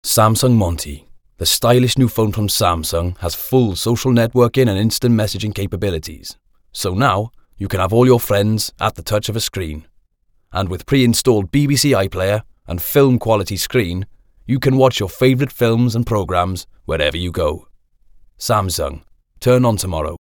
Actor-Name-Witheld-Accents.mp3